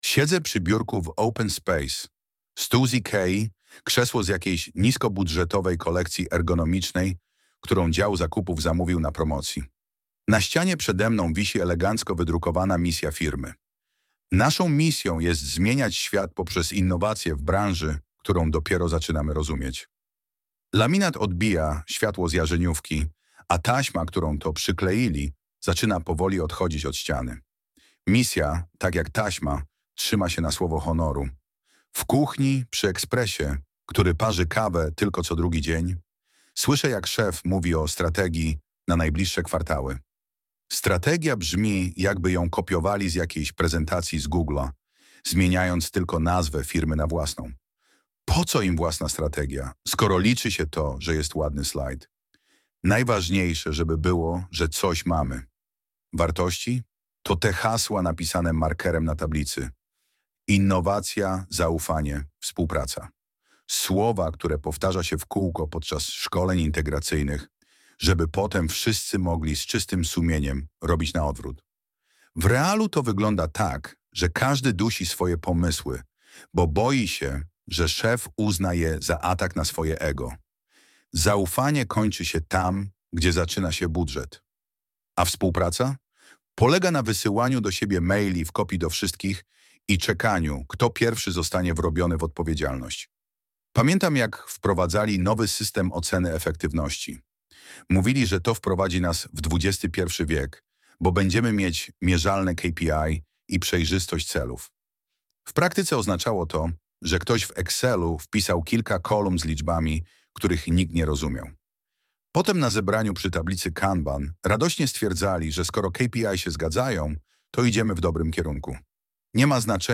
Pobierz swój darmowy fragment audiobooka